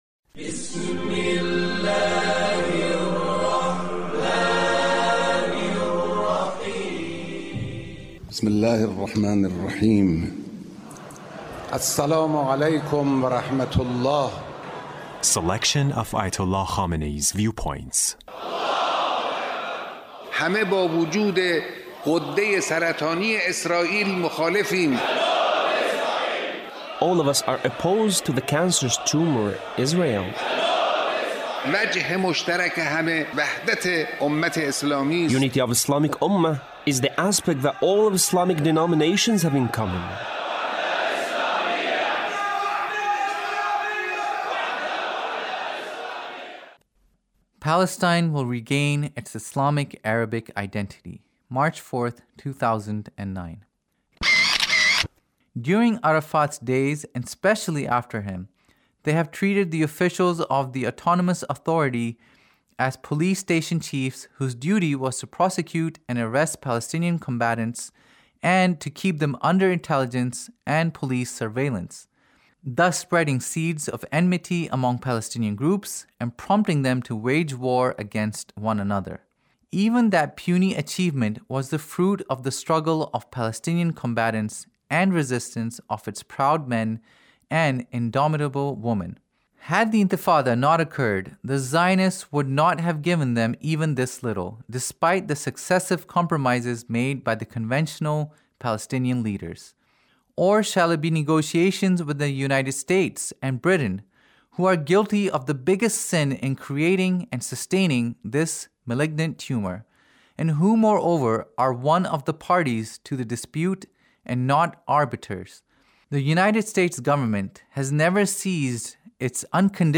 Leader's Speech (1879)